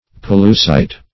Pollucite \Pol"lu*cite\, n. [See Pollux, and 4th Castor.]